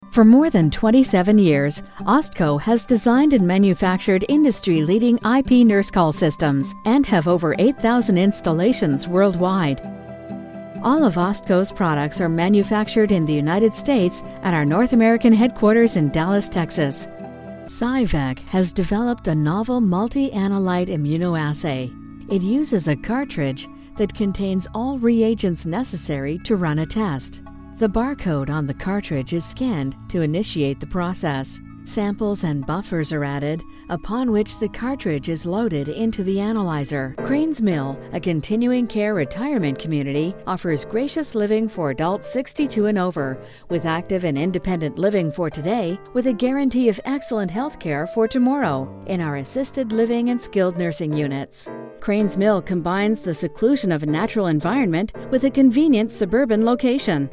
Inglês (canadense)
Vídeos Corporativos
Microfone Neumann TLM103, pré-amplificador SSL2, software Goldwave.